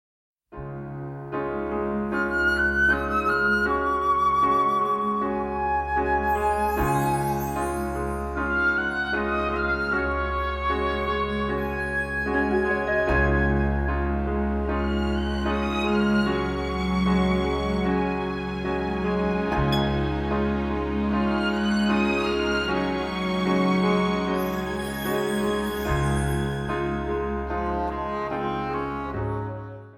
• liebliche Klänge und Melodien, die Sie motivieren